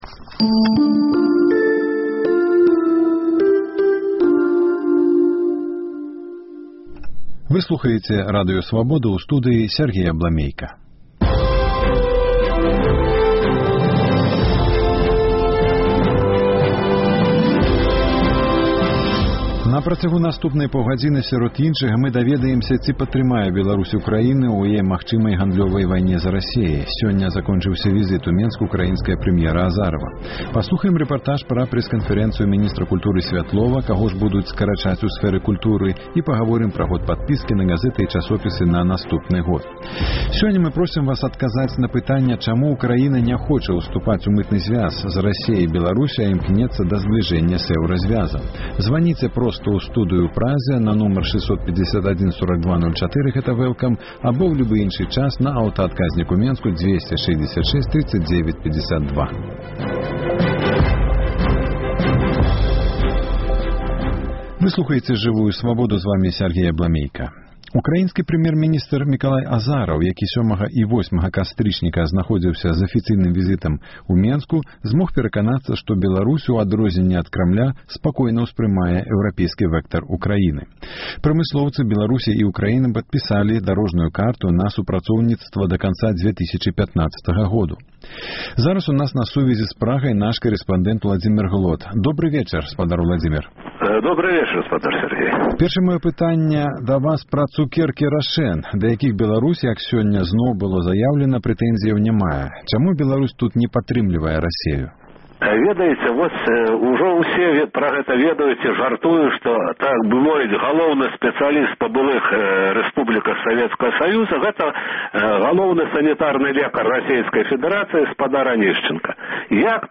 Ці падтрымае Беларусь Украіну ў гандлёвай вайне з Расеяй? Эфір